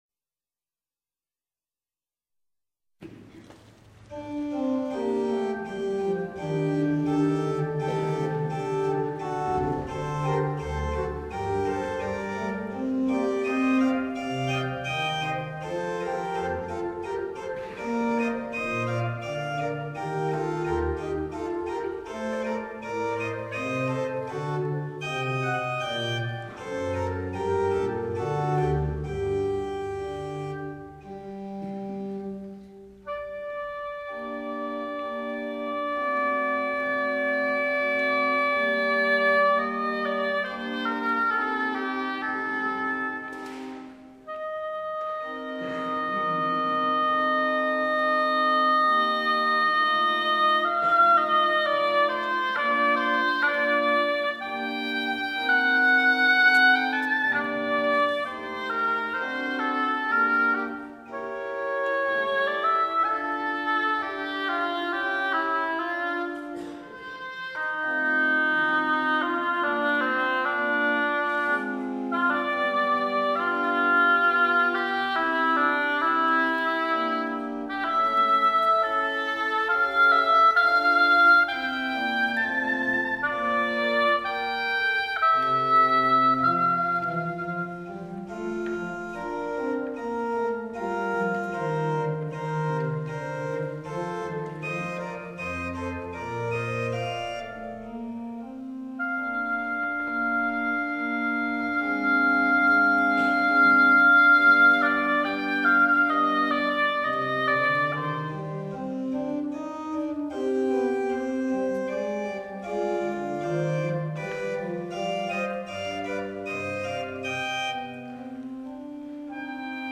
Concerto dell'Epifania
Chiesa di San Giorgio